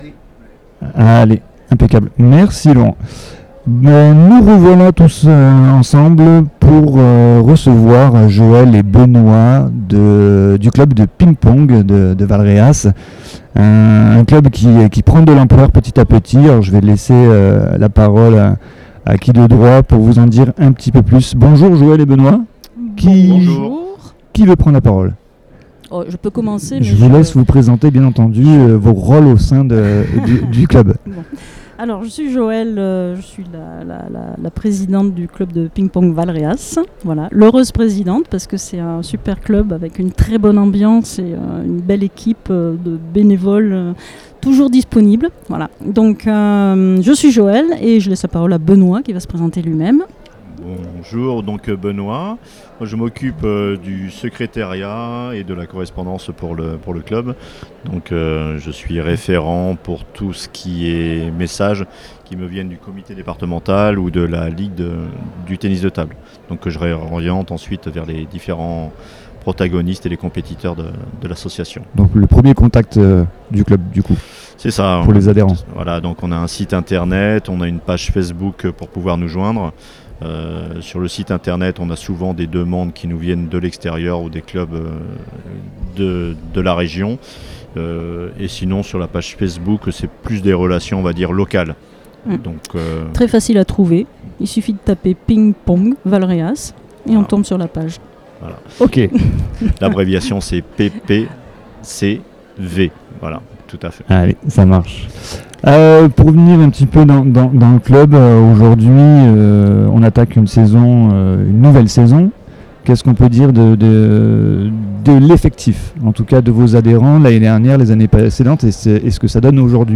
INTERVIEW PING PONG CLUB VALREAS